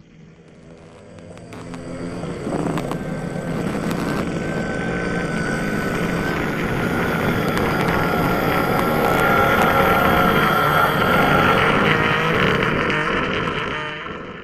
affectionate_scream.ogg